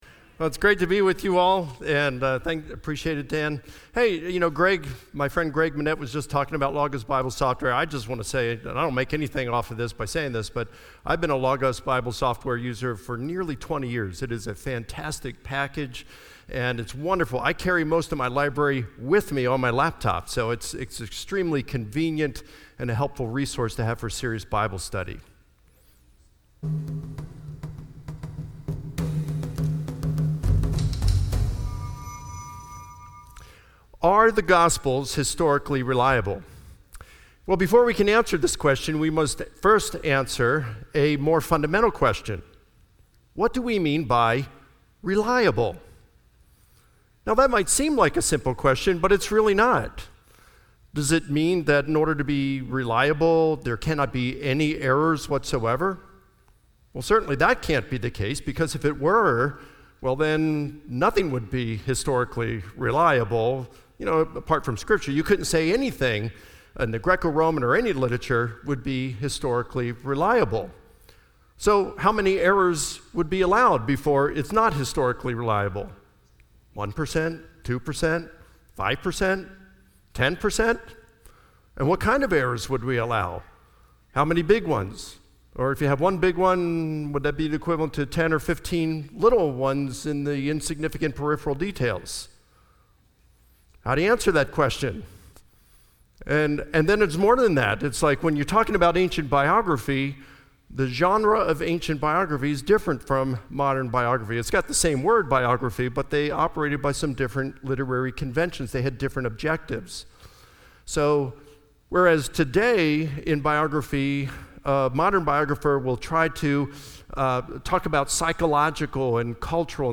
Insight Is 2020: Apologetics Conference